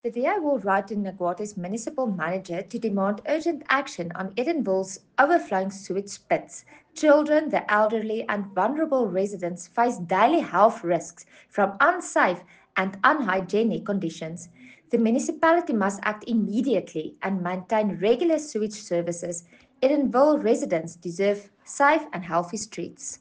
English and Afrikaans soundbites by Cllr Carina Serfontein and